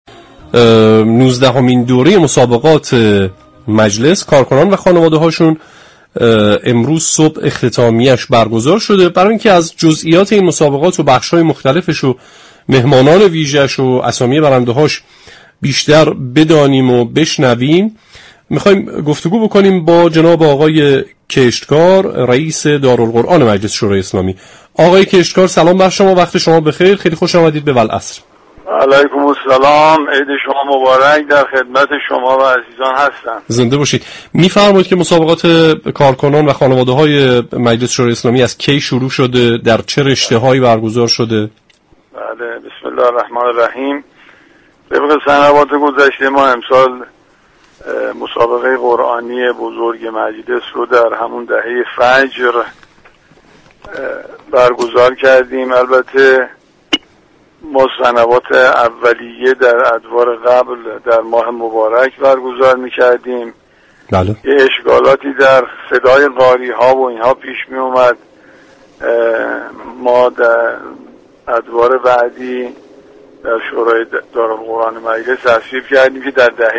در گفتگو با برنامه والعصر رادیو قرآن